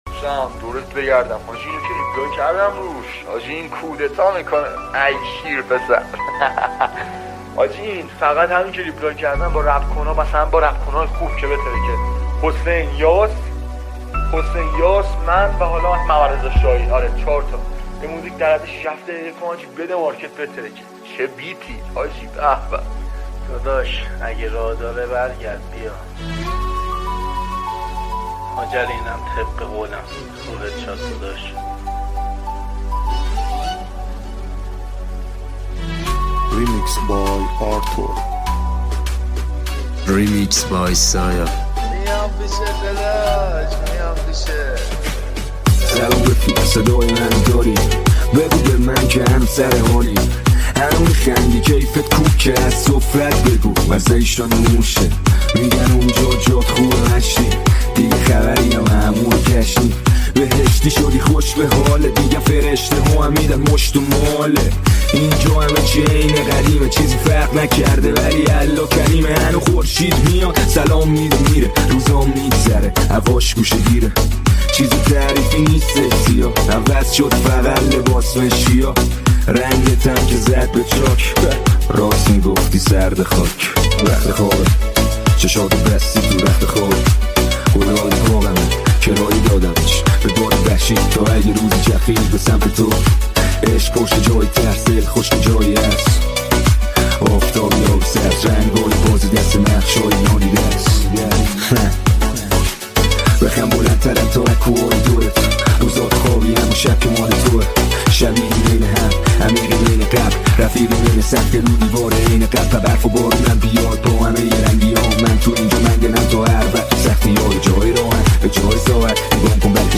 ریمیکس رپ ترکیبی